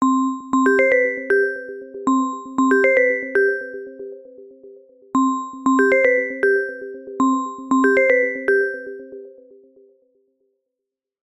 Sound Effects
Samsung Galaxy Bells (Old)